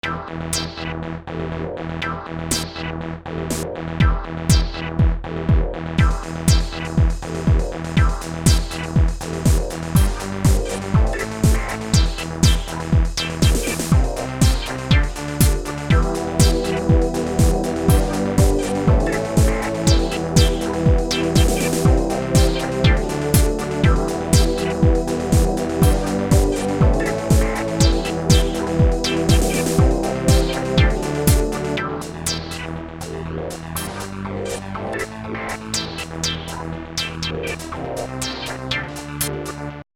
my 100% MDC1 mix (overdub, no fx)